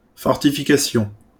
IPA/fɔʁ.ti.fi.ka.sjɔ̃/